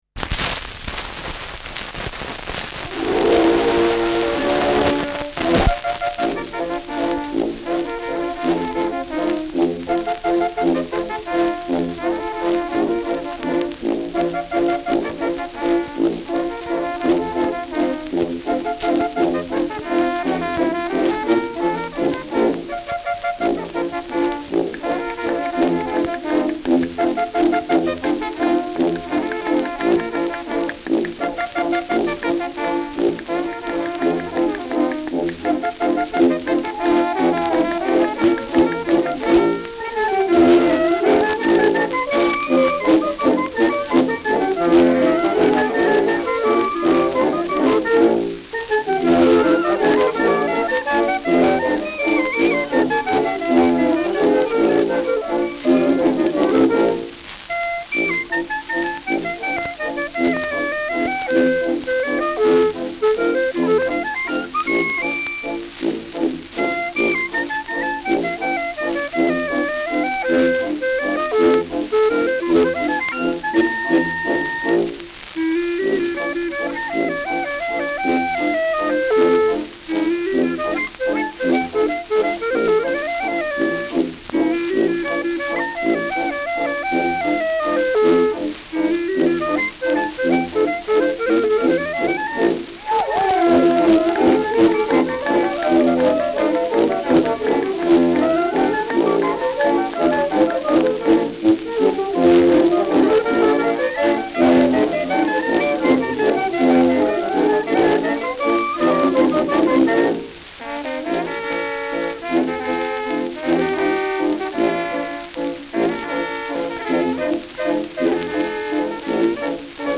Schellackplatte
präsentes Rauschen : Tonnadel „rutscht“ im ersten Takt über einige Rillen : leichtes Leiern : präsentes Nadelgeräusch
[München] (Aufnahmeort)